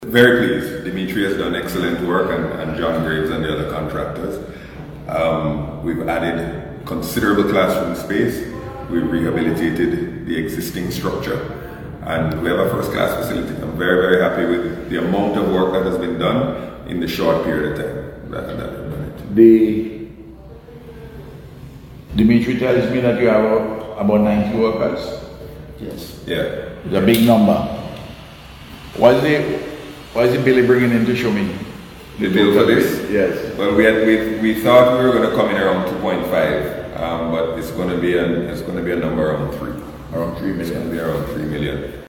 He was speaking during a tour of the campus with Prime Minister Dr Ralph Gonsalves and the contractors yesterday ahead of its expected opening tomorrow.